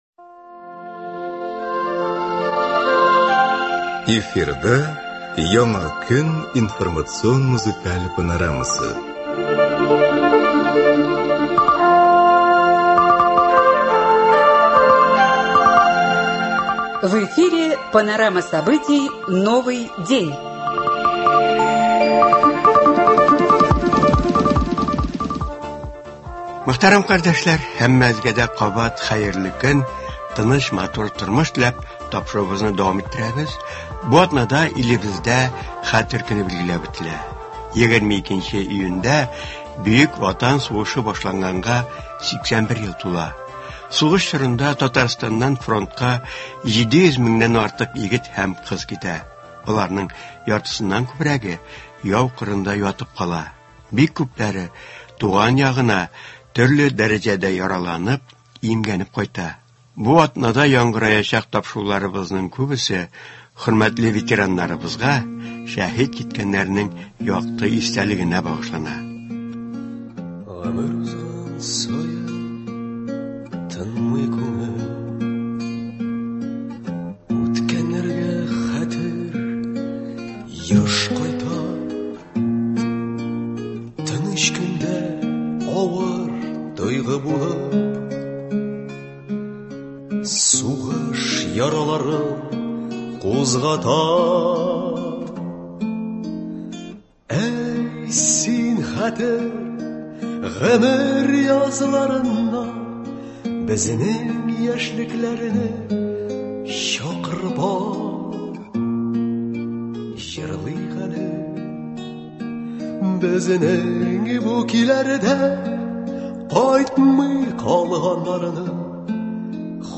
Татарстан дәүләт радиосы шушы вакыйгага багышланган тапшырулар циклын башлап җибәрә. Бу программада Бөек Ватан сугышы ветераннары һәм сугыш чоры балалары катнашында төрле елларда әзерләнгән язмалар файдаланыла.